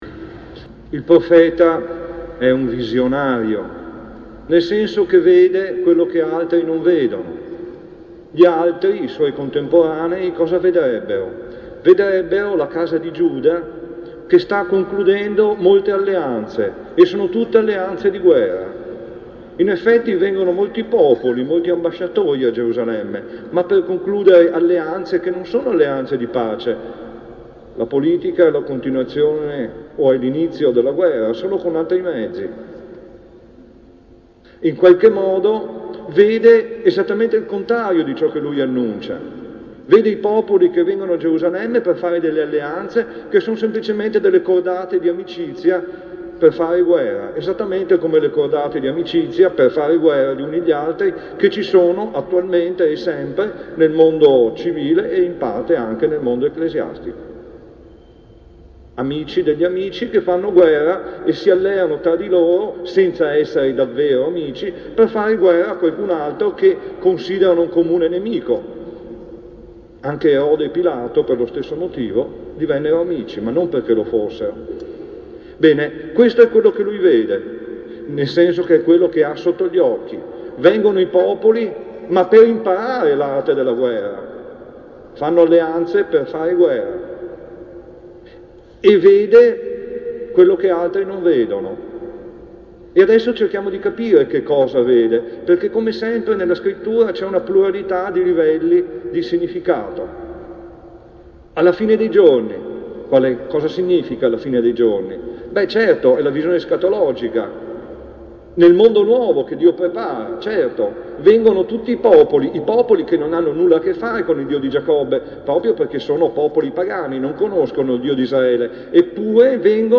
Omelie - Gesuiti
A_I-Avvento.mp3